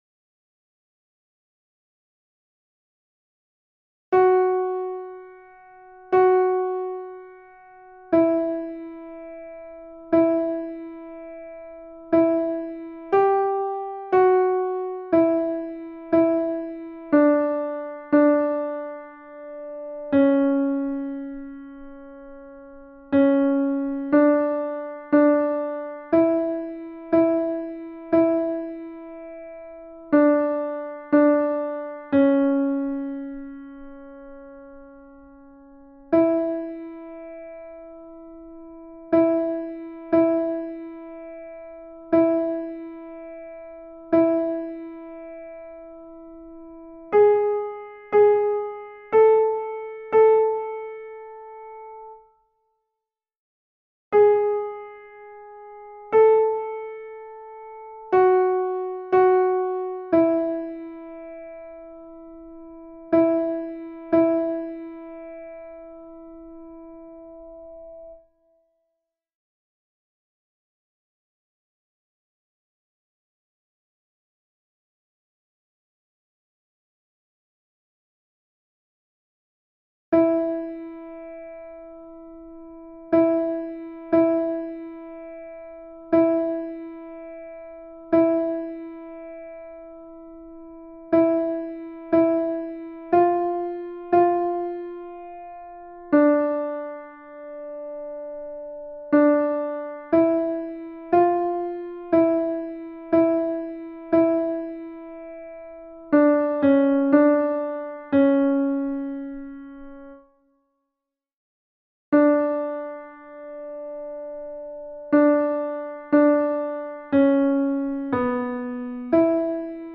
MP3 versions chantées
Alto (piano)
Ave Verum Corpus Mozart Alto Mp 3